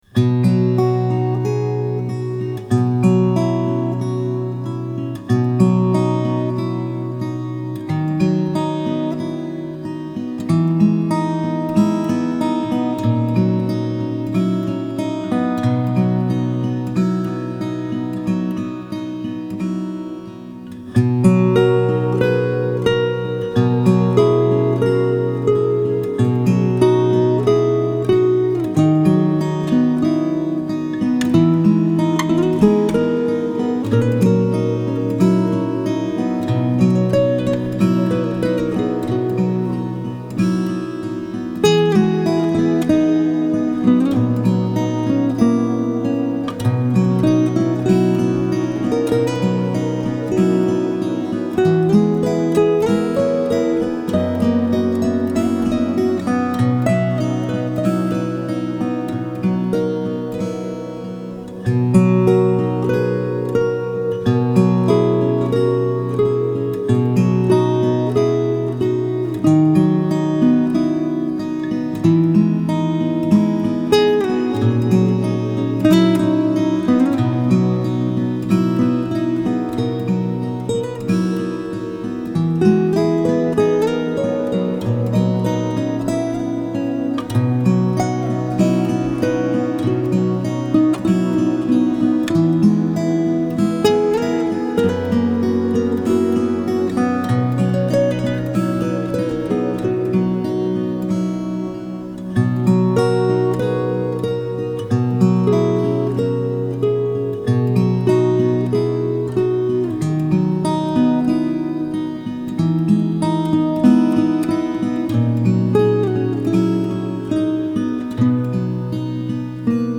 آرامش بخش , گیتار , موسیقی بی کلام